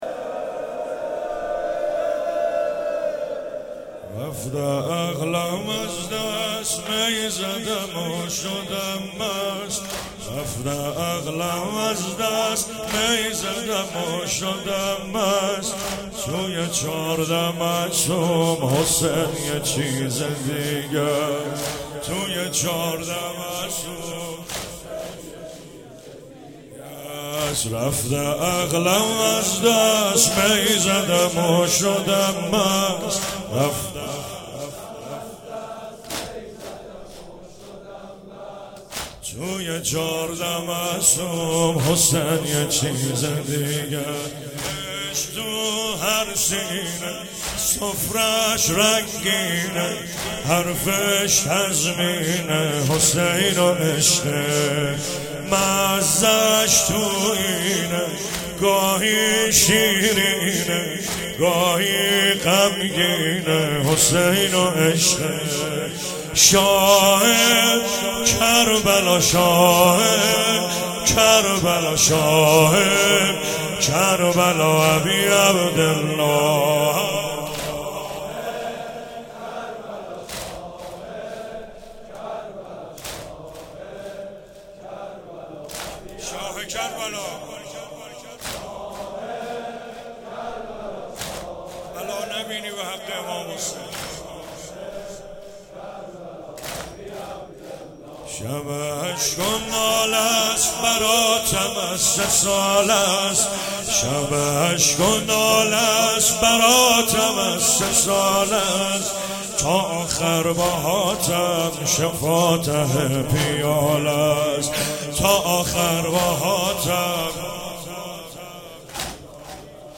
شب سوم رمضان 95، حاح محمدرضا طاهری
06 heiat alamdar mashhad.mp3